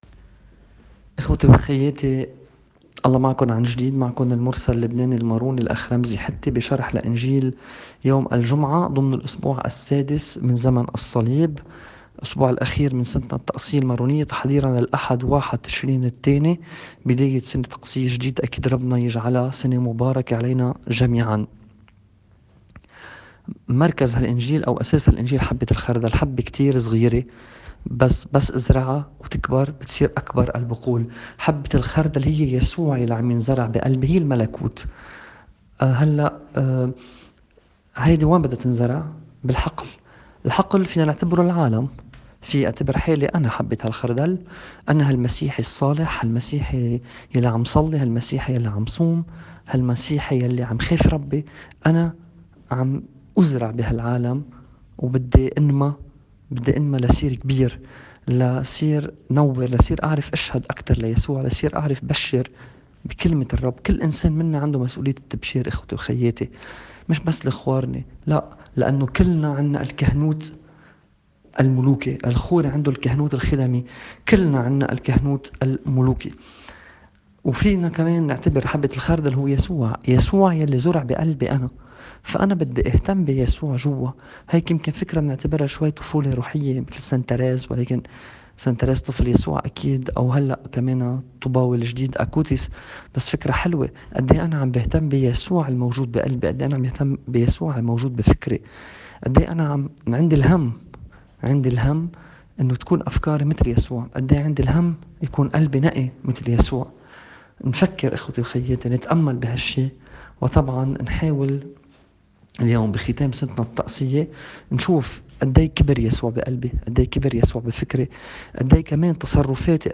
تأمّل في إنجيل يوم ٣٠ تشرين الأول ٢٠٢٠.mp3